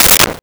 Sword Whip 01
Sword Whip 01.wav